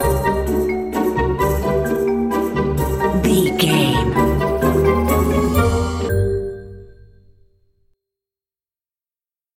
Uplifting
Ionian/Major
D
Slow
flute
oboe
strings
orchestra
cello
double bass
percussion
violin
sleigh bells
silly
goofy
comical
cheerful
perky
Light hearted
quirky